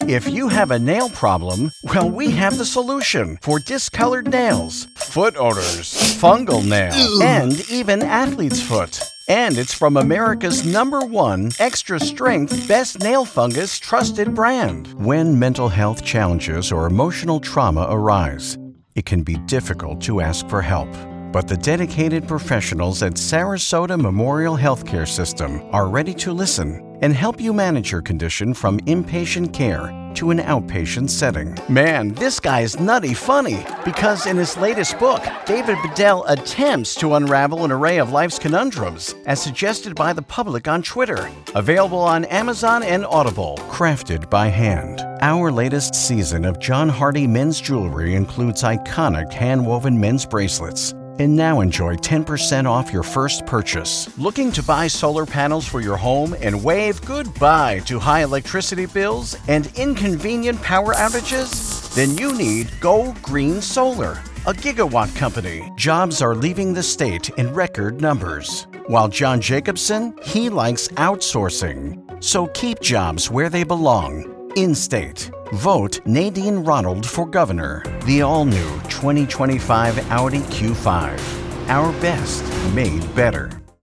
English (American)
Believable
Sincere
Energetic